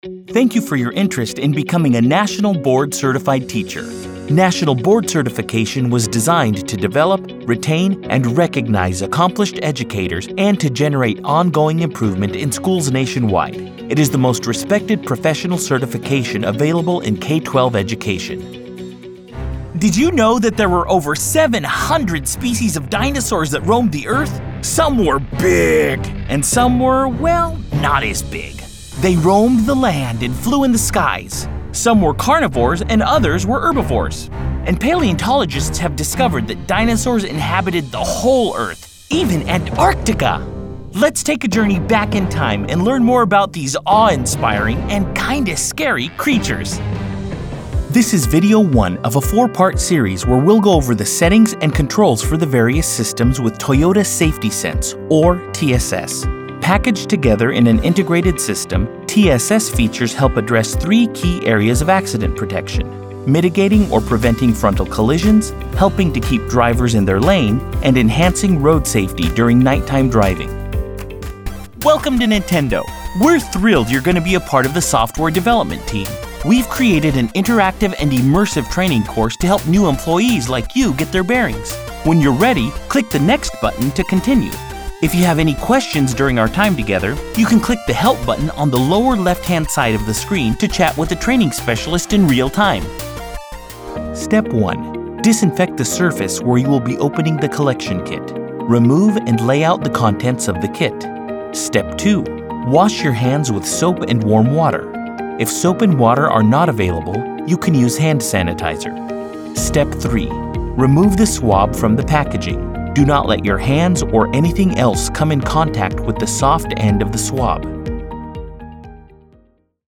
eLearning/Corporate training demo
• Friendly guy-next-door;
• Caring, empathetic;
• Professional, business, corporate; instructor;